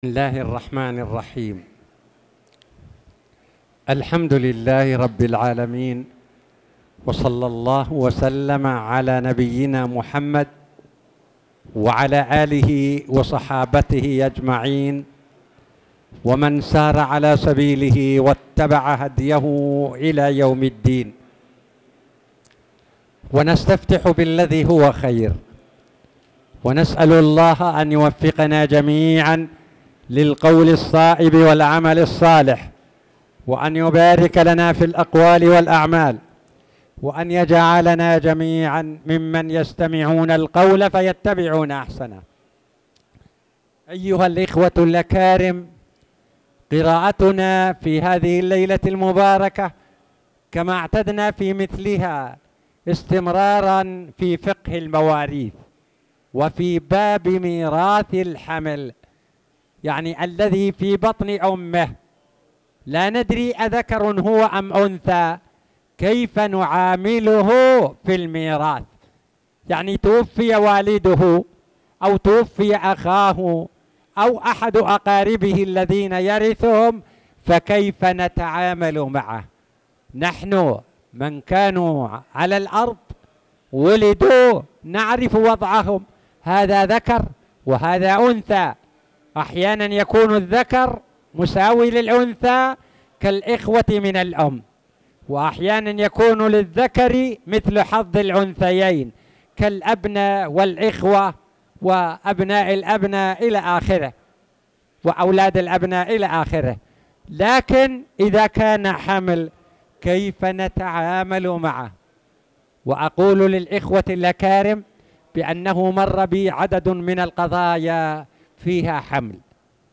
تاريخ النشر ١١ ذو القعدة ١٤٣٧ هـ المكان: المسجد الحرام الشيخ